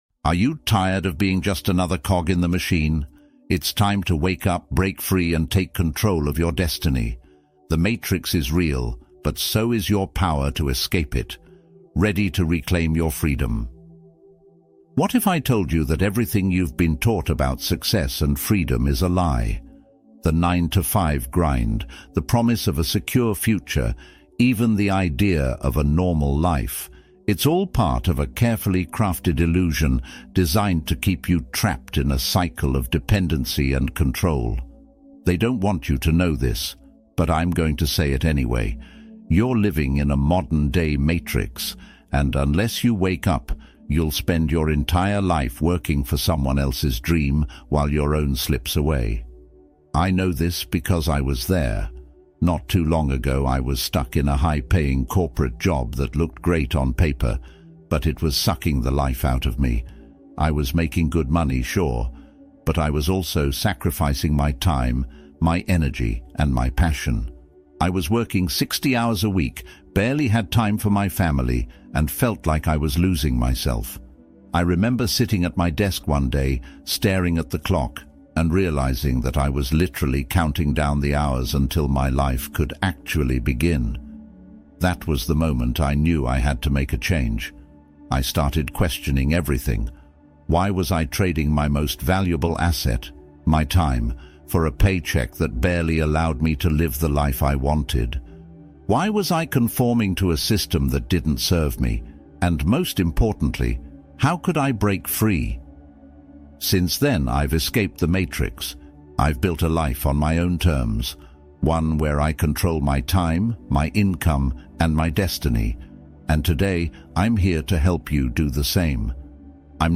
Deep Relaxation Soundscape for Focus and Recovery